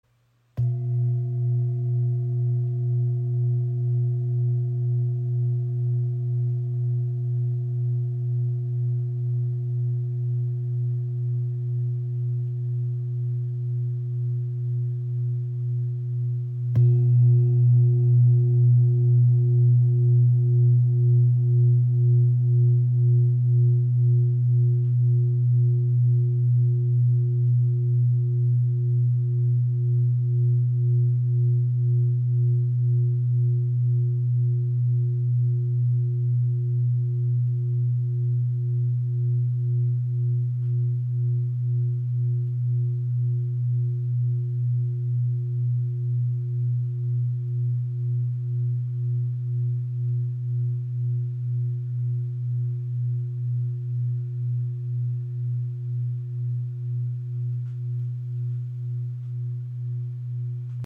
Klangschale mit Shri Yantra | ø 24 cm | Ton ~ A# / Bb | Sedna-Ton (121,51 Hz)
Handgefertigte Klangschale aus Kathmandu
• Icon Inklusive passendem rotem Filzschlägel
Ihr obertonreicher Klang im Ton E ist klar und zentrierend.
In der Klangarbeit wirkt er erdend, lösend und regenerierend, mit stark introspektivem Charakter.